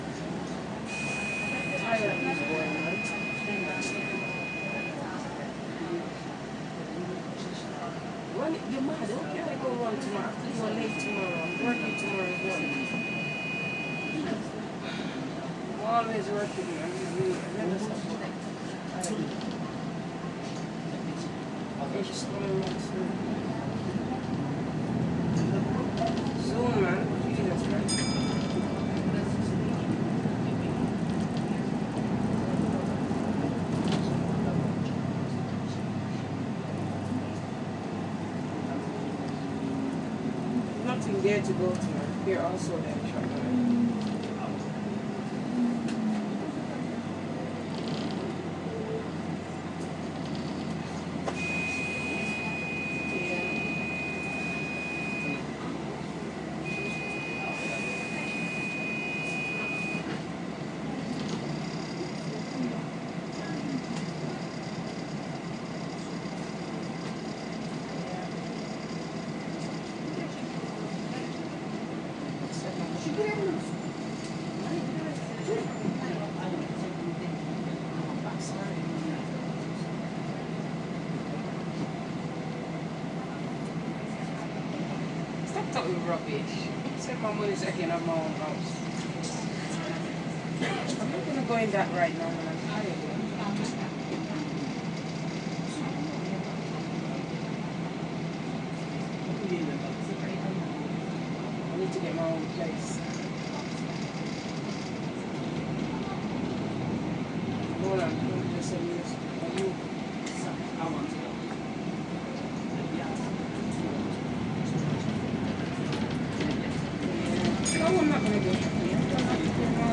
2 minutes of top deck on a #31 London bus on a quiet Sunday evening.